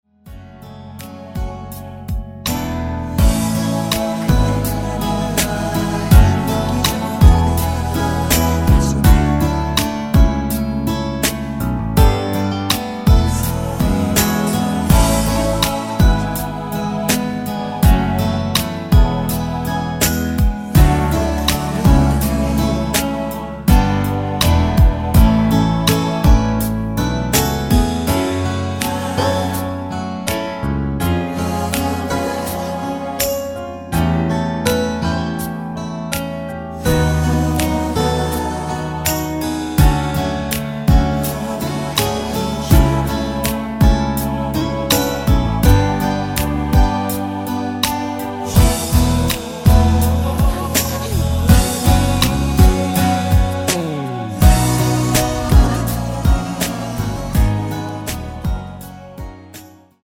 코러스 포함된 MR 입니다 (미리듣기 참조)
앞부분30초, 뒷부분30초씩 편집해서 올려 드리고 있습니다.
중간에 음이 끈어지고 다시 나오는 이유는